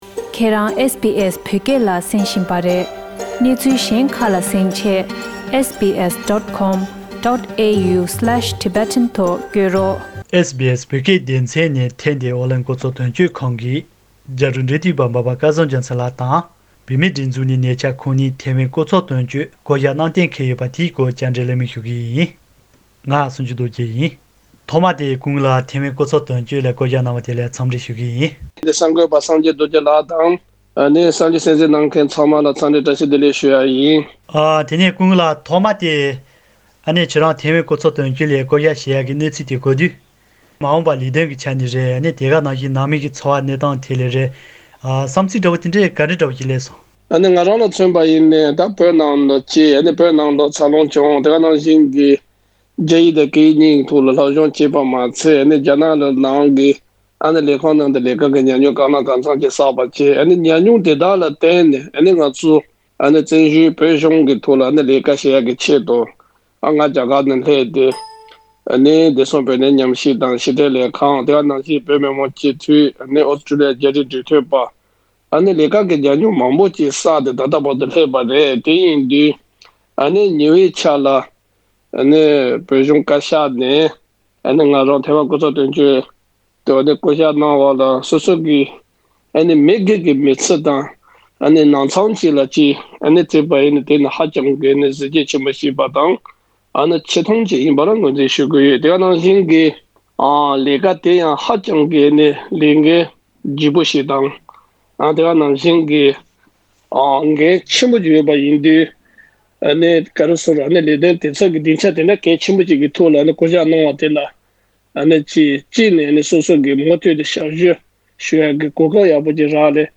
ཨོ་གླིང་རྒྱ་རིགས་འབྲེལ་མཐུད་པར་ཁོང་གི་དོན་གཅོད་གསར་པའི་ལས་འཁུར་སྐོར་བཅར་འདྲི།